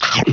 File:Big Chomp.oga
Sound effect used in Donkey Kong Country 2: Diddy's Kong Quest and Donkey Kong Country 3: Dixie Kong's Double Trouble!.
Big_Chomp.oga.mp3